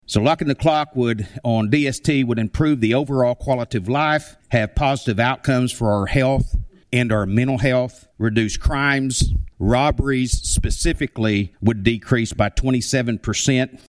CLICK HERE to listen to commentary from Sponsor Blake Stephens.